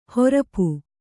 ♪ horapu